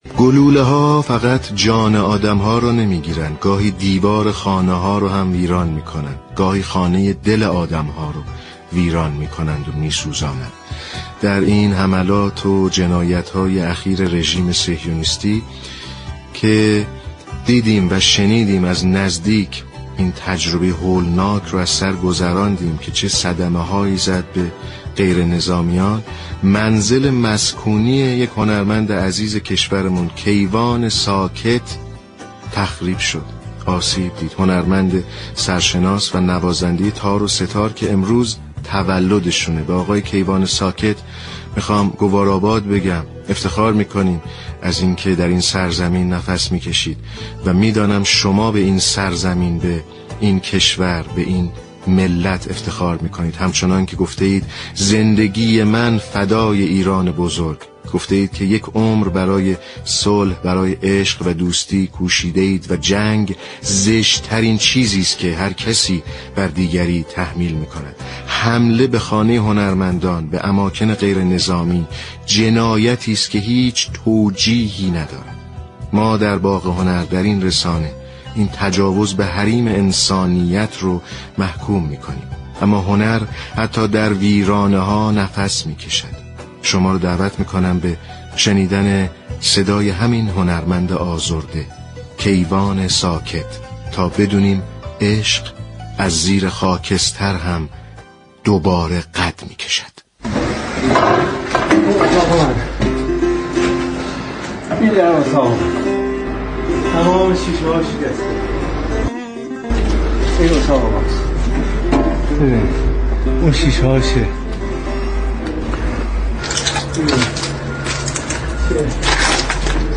به گزارش شبكه رادیویی ایران، برنامه باغ هنر در سالروز تولد كیوان ساكت صدای این هنرمند برجسته ایرانی را برای مخاطبان پخش كرده است. كیوان ساكت می‌گوید:در جنگ اخیر، زندگی‌ام نابود شد اما زندگی من فدای ایران بزرگ.